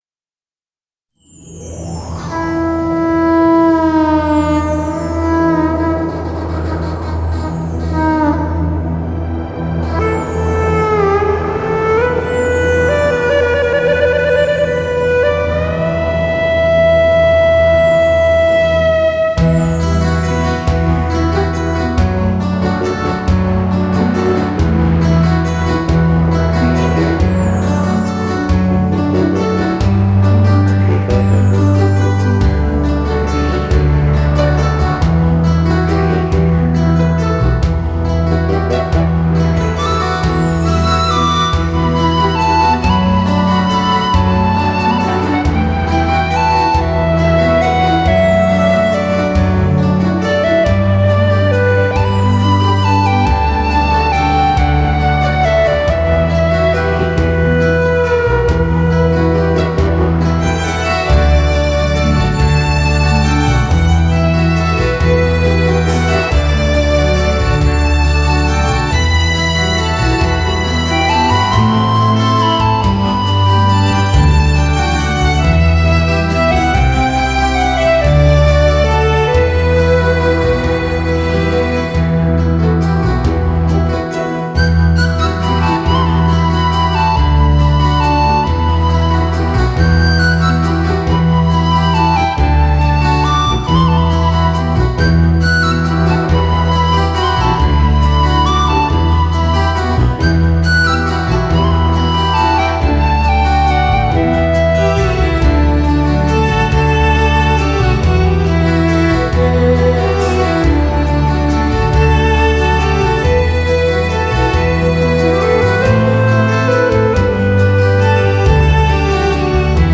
2周前 纯音乐 11